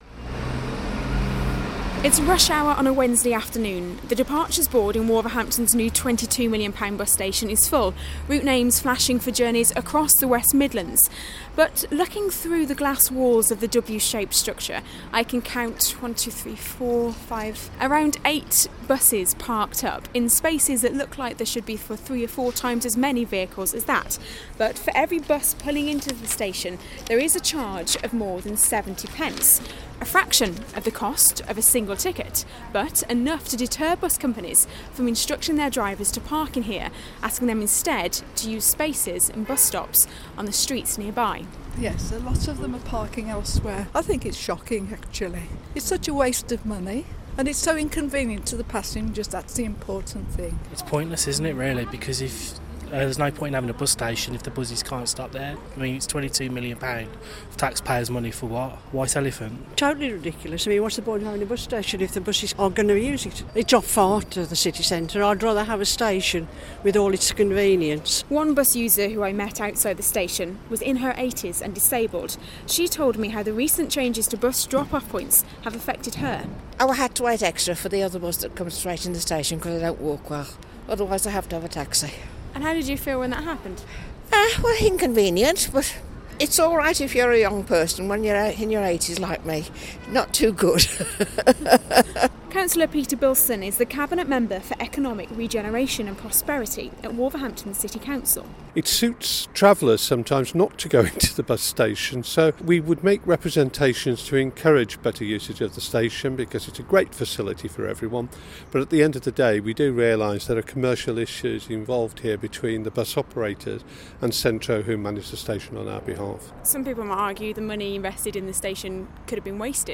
(Broadcast on BBC WM in April 2013) There are calls for Wolverhampton bus station to be put to greater use -- after complaints some services in the city are bypassing it.